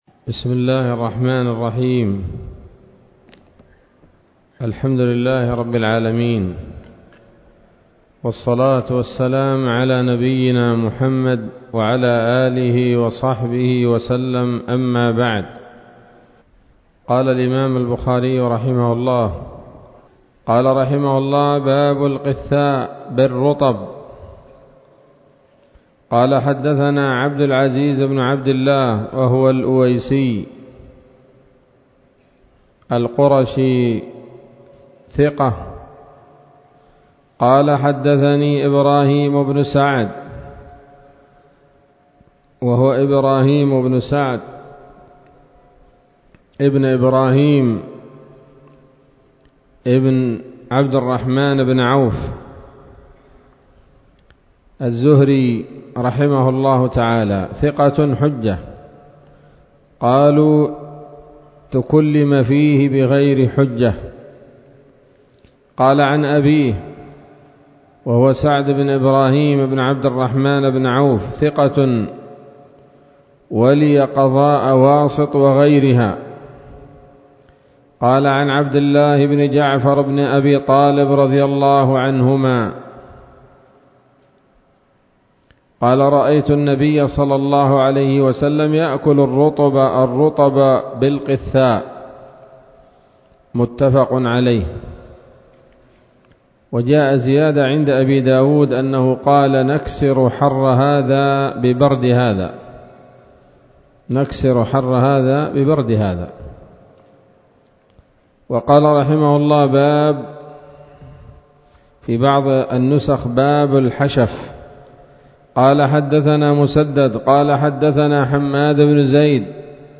الدرس الثاني والعشرون من كتاب الأطعمة من صحيح الإمام البخاري